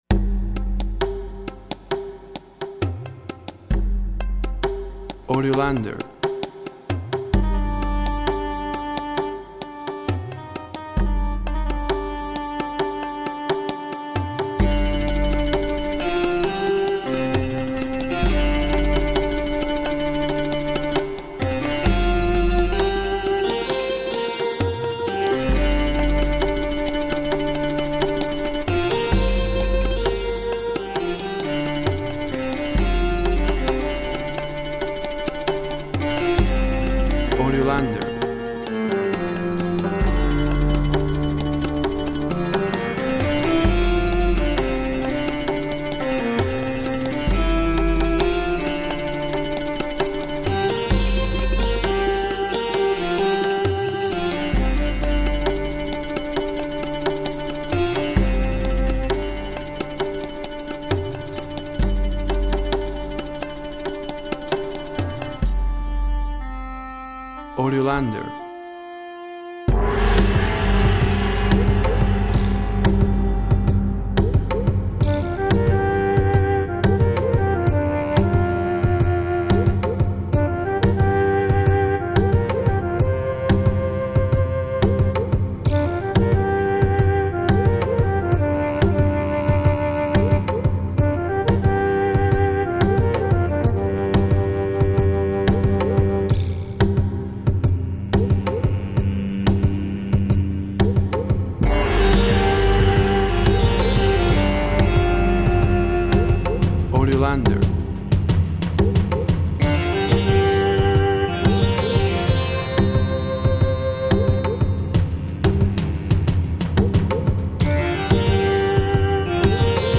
Tempo (BPM) 70